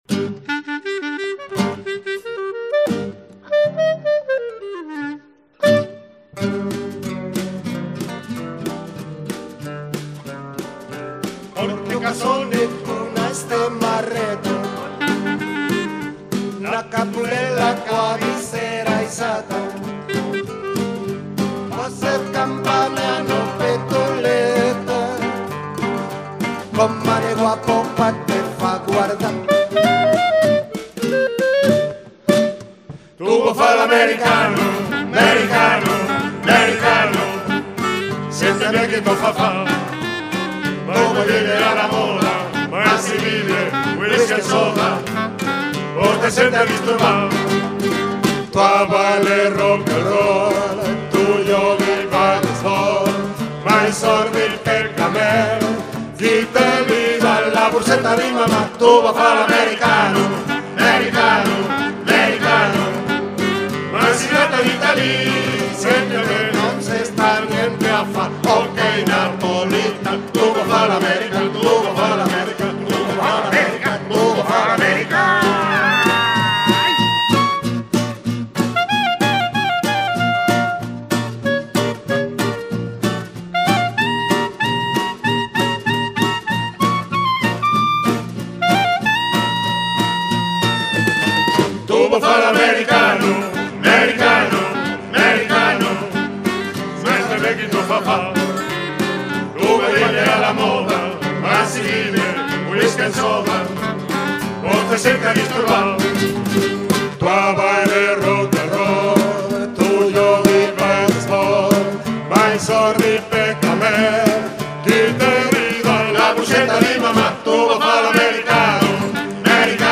CONCIERTO 2014, en directo